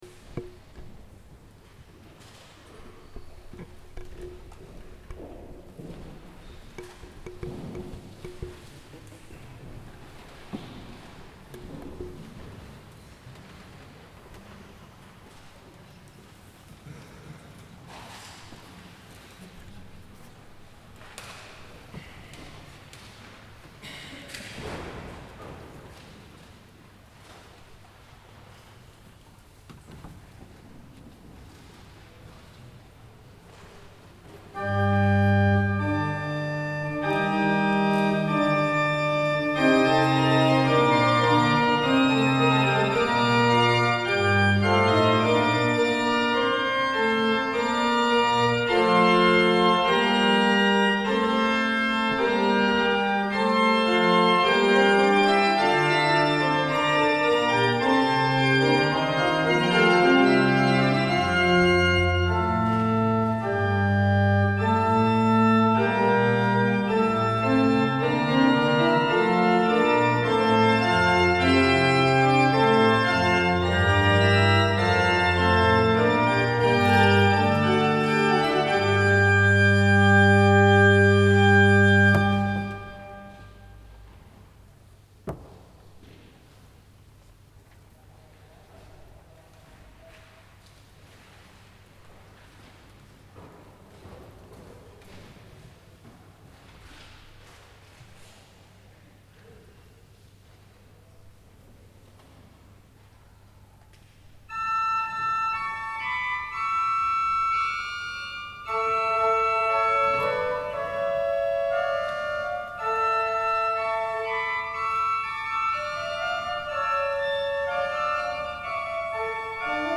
L’arte organistica italiana: maestri, allievi ed epigoni. Concerto d’organo
presso il Tempio della Beata Vergine del Soccorso